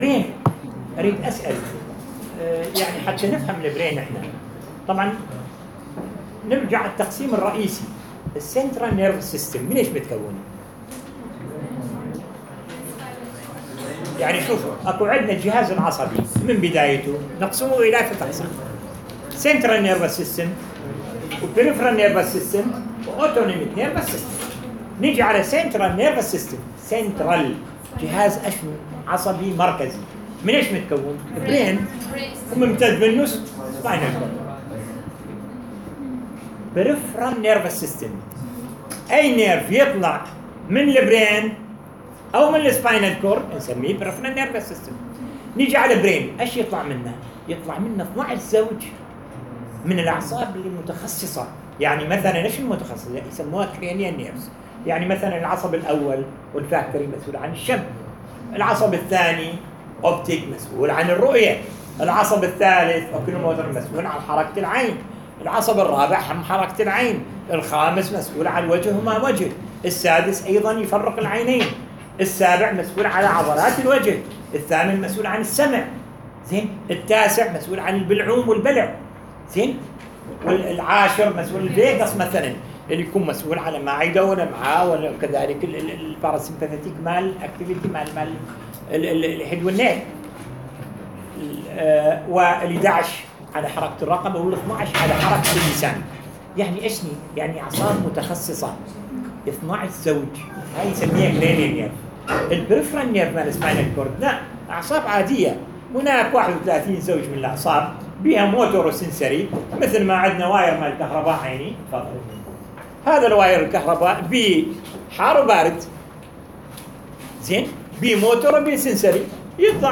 Neuroanatomy voice recording